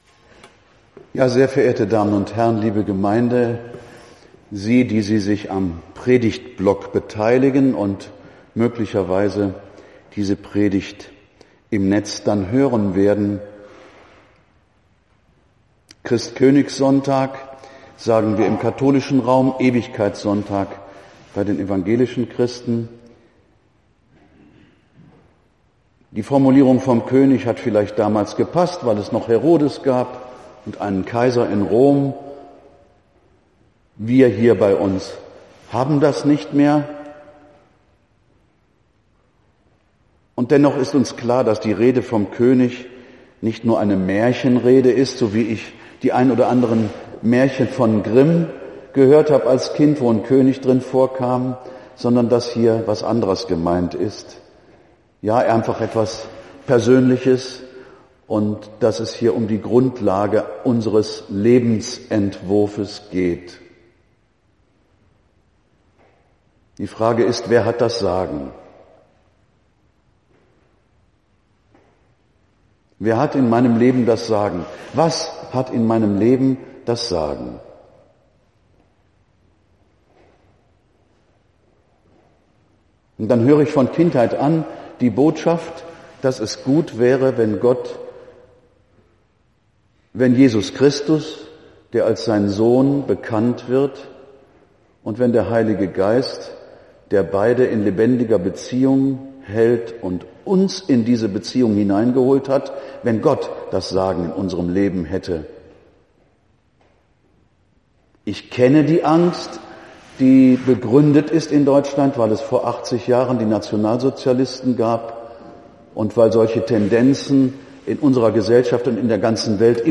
Die Predigt am 23. November 18h Hl. Geist: Kreuzigung